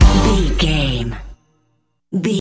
Uplifting
Aeolian/Minor
Fast
drum machine
synthesiser
electric piano
Eurodance